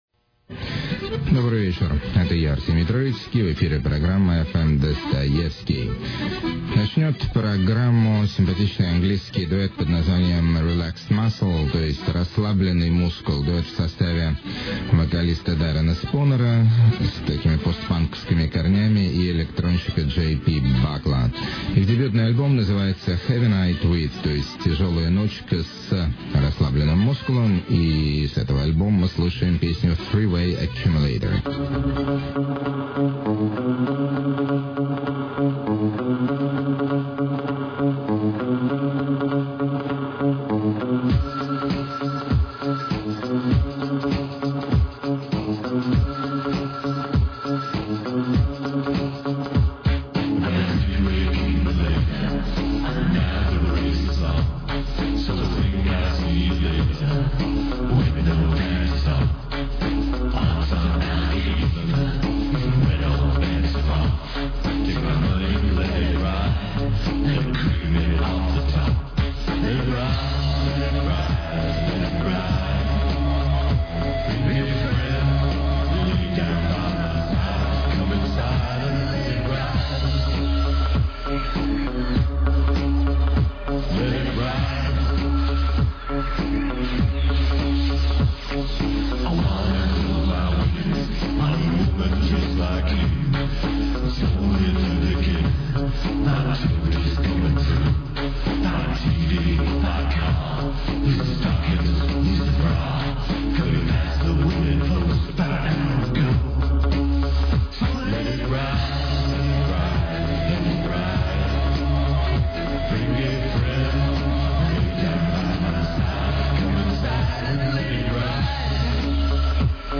ebm meets trash
cacophony blues
instrumental electropop groove
wild style vocal flamenco
rocky trip-hop
epic drone rock
weird-ish folk rock
dadaistic blip-hop